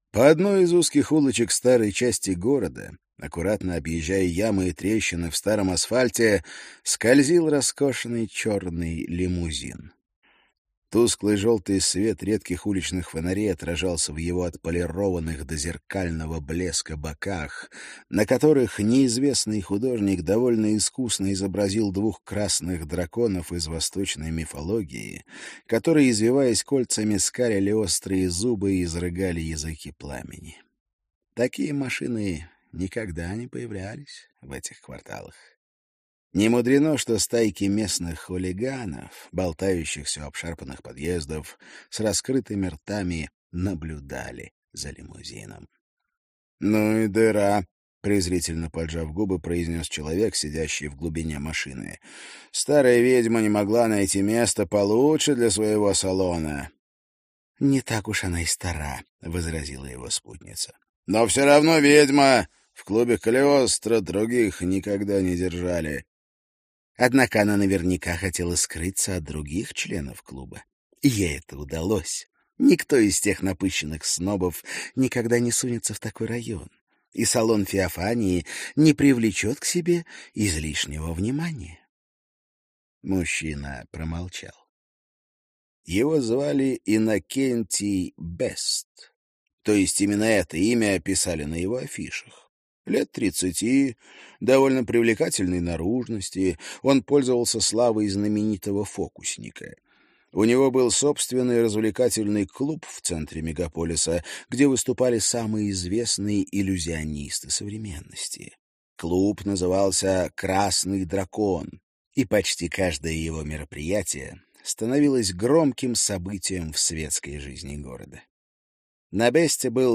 Аудиокнига Зерцалия. Трианон | Библиотека аудиокниг